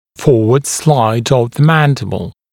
[‘fɔːwəd slaɪd əv ðə ‘mændɪbl][‘фо:уэд слайд ов зэ ‘мэндибл]соскальзывание вперед нижней челюсти, смещение вперед нижней челюсти